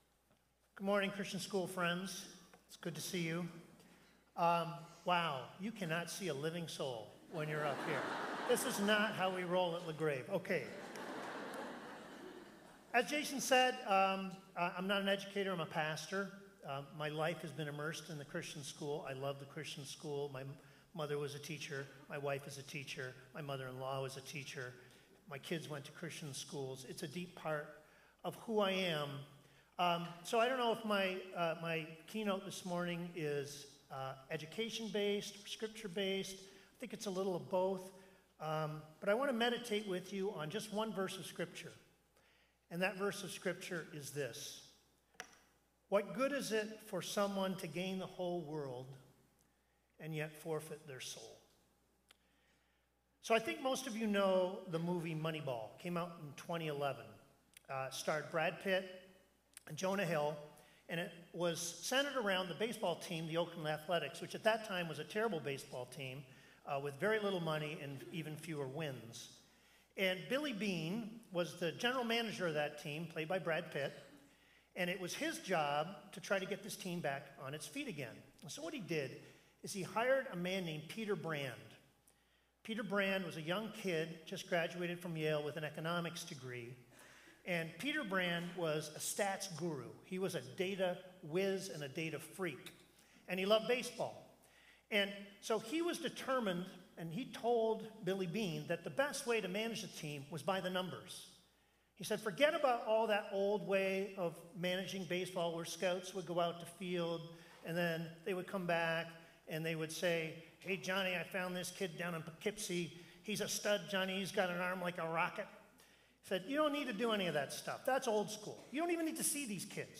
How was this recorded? The recordings are from the annual CEA Convention.